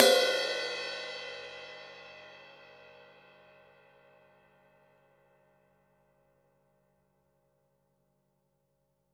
Index of /90_sSampleCDs/Total_Drum&Bass/Drums/Cymbals
ride_cym4.wav